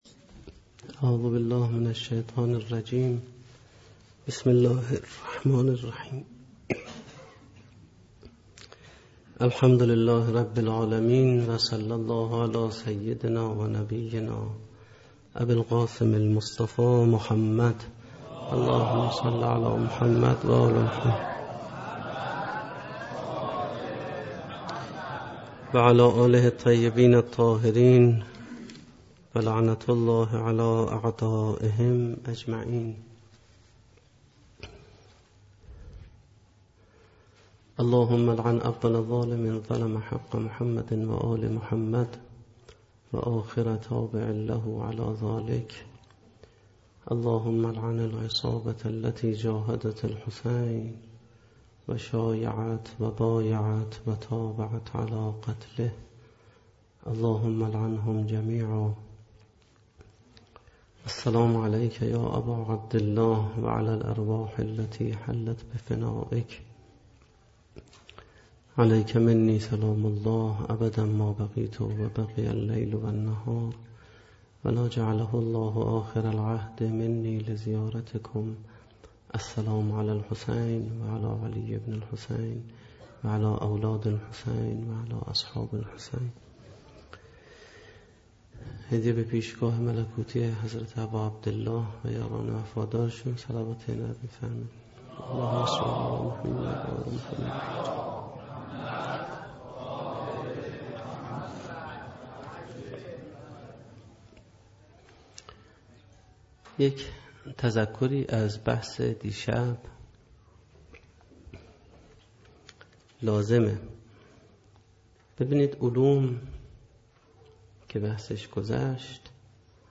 سخنرانی